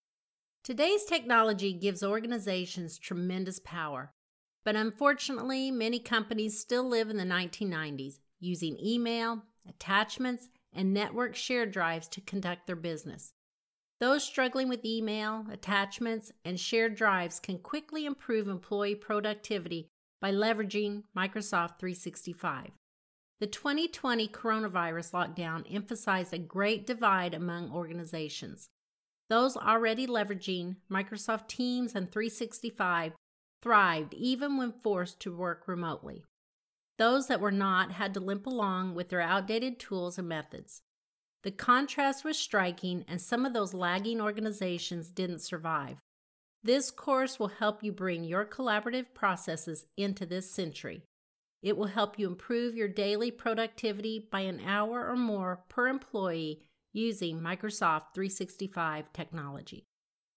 The only editing I have done so far is to do a noise profile of a silent portion, noise reduction with that, and then silenced the non-speaking portions.
The voice has a little “recording in the kitchen” sound.
Volume is good, if slightly low.
Any reason you submitted in stereo—two blue waves?
The pauses are a little off.